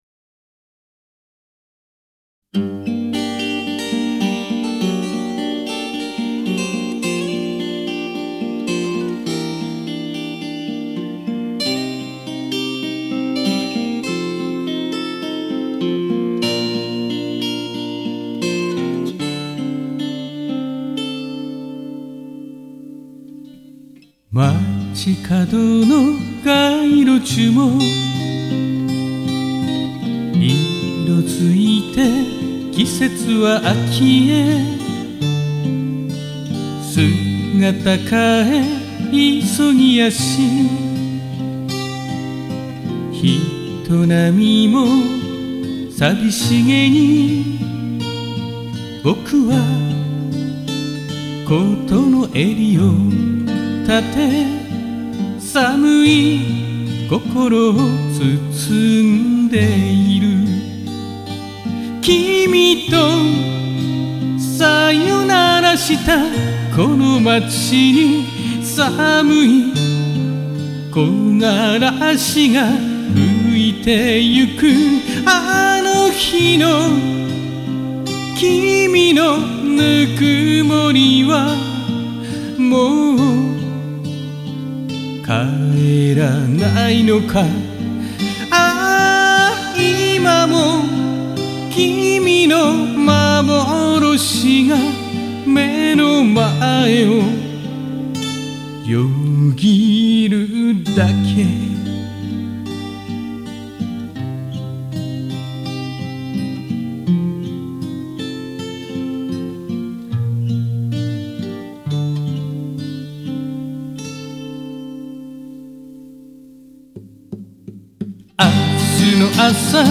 どういうリズムでやろうかと色々考えましたが、ここに落ち着きました。
ギターは学生時代から使ってるキャッツ・アイです。